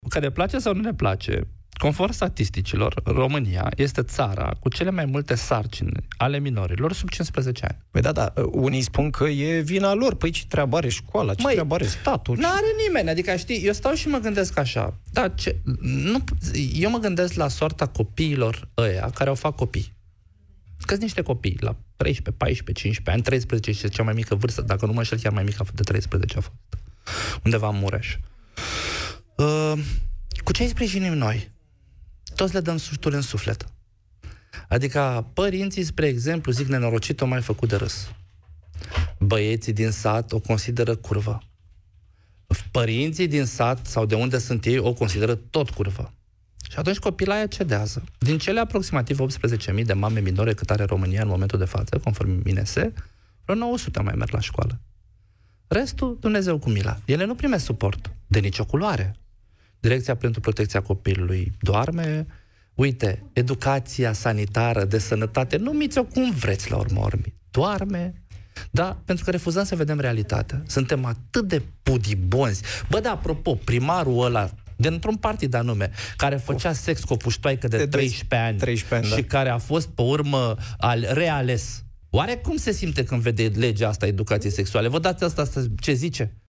Invitat la emisiunea Piața Victoriei, marți seară la Europa FM